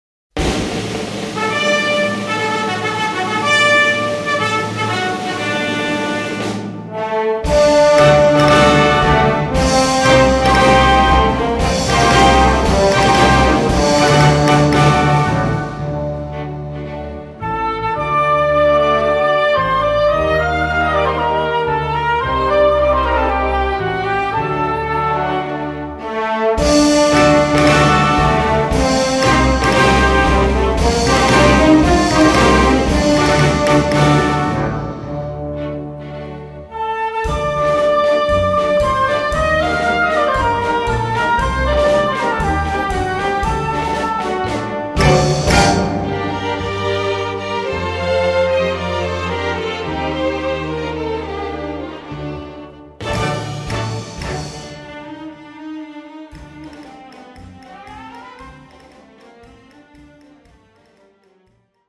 Voicing: Orchestra